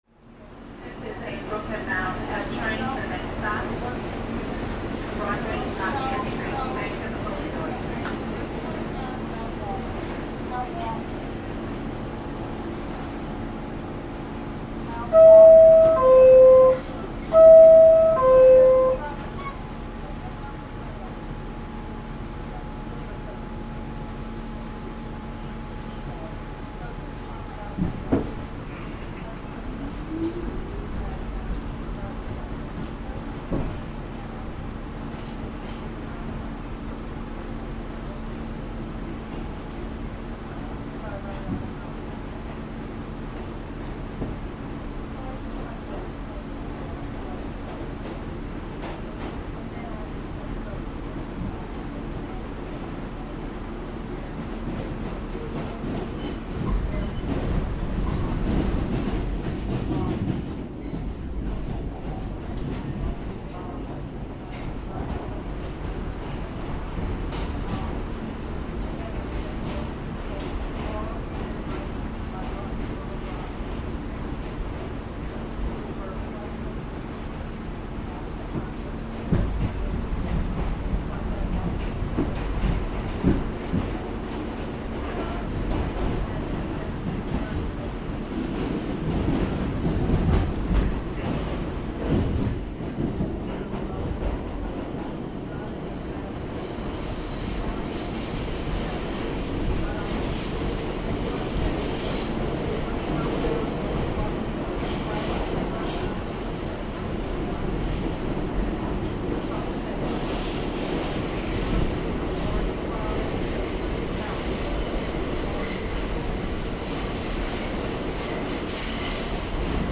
The sounds of the New York subway - here, we take a trip from Washington Square to 2nd Avenue in Manhattan.